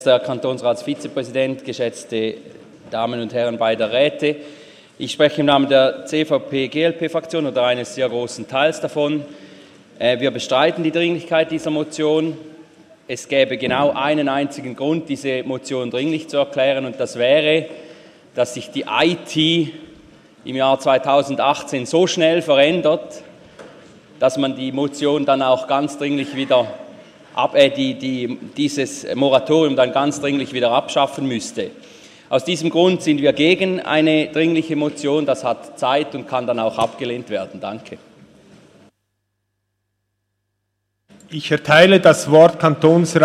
Session des Kantonsrates vom 26. bis 28. November 2018